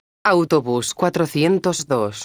megafonias exteriores
autobus_402.wav